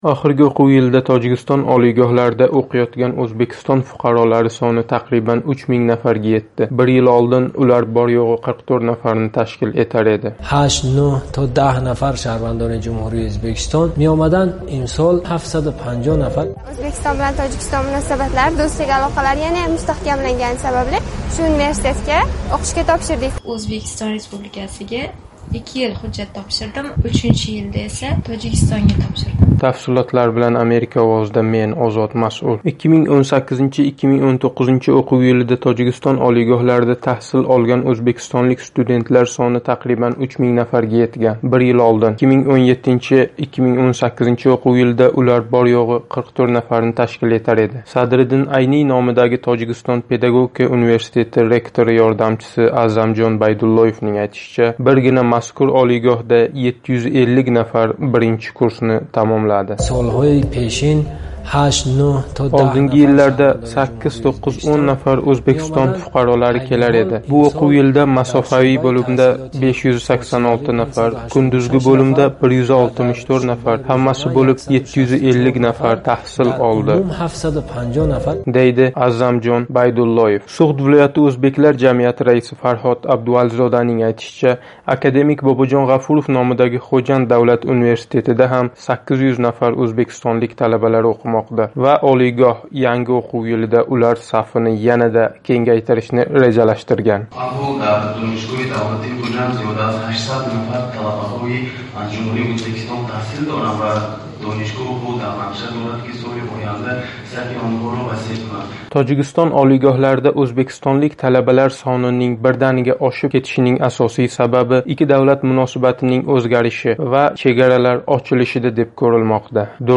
Tojikistonda tahsil olayotgan o'zbekistonlik talaba bilan suhbat